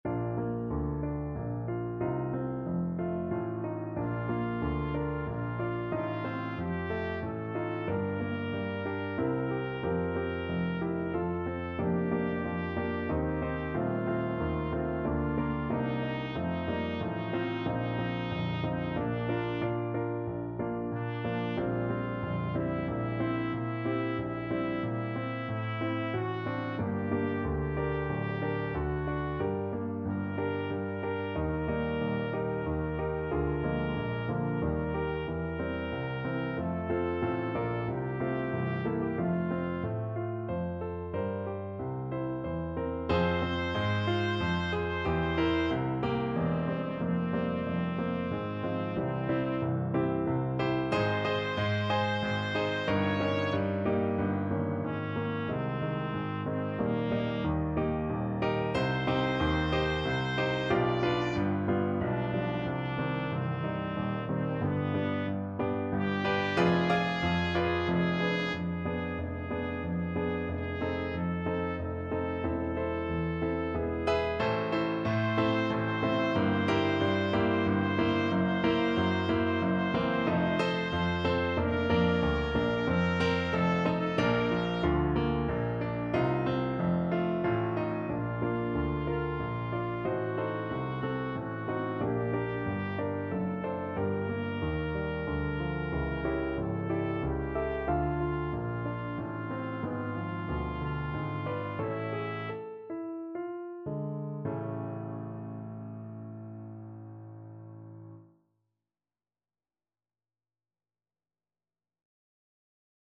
Trumpet version
3/4 (View more 3/4 Music)
~ = 92 Larghetto
Trumpet  (View more Easy Trumpet Music)
Classical (View more Classical Trumpet Music)